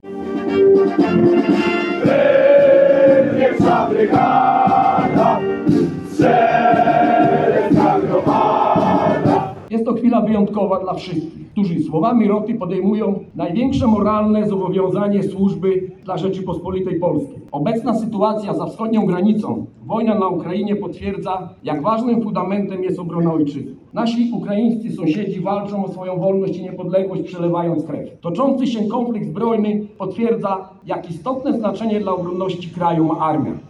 W Sieradzu żołnierze dobrowolnej zasadniczej służby wojskowej, którzy szkolenie odbyli w sieradzkiej jednostce, złożyli uroczystą przysięgę wojskową.